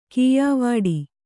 ♪ kīyāvāḍi